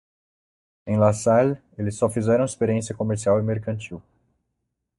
/meʁ.kɐ̃ˈt͡ʃiw/